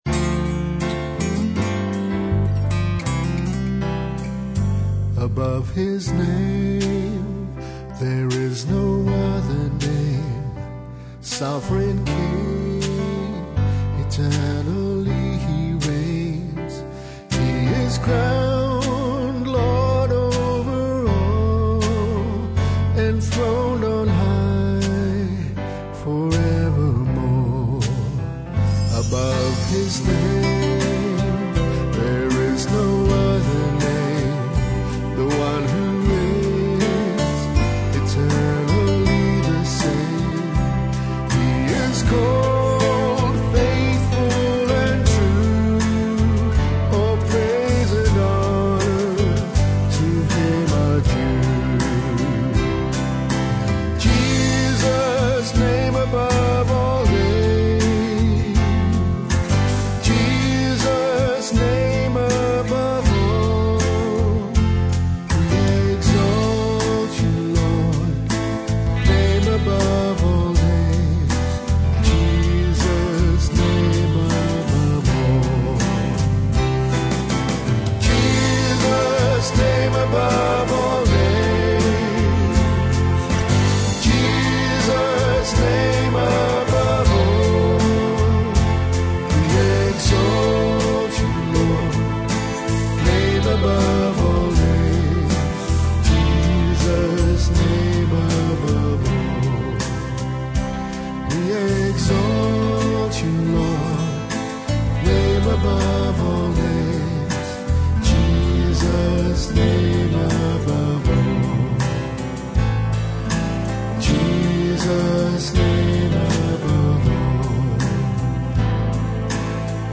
Brilliant worship song